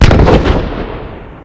plane_preexp3.ogg